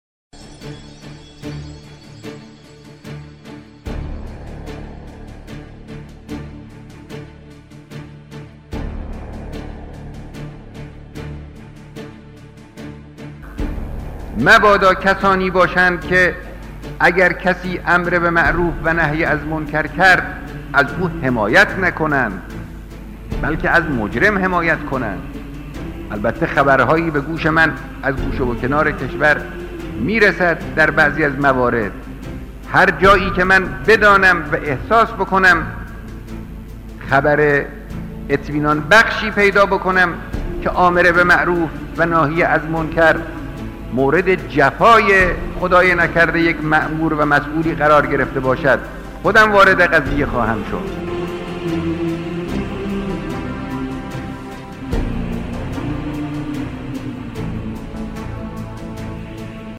• آمر به معروف, حمایت, امام خامنه ای, سخنرانی, امر به معروف, رهبری, ناهی از منکر, نهی از منکر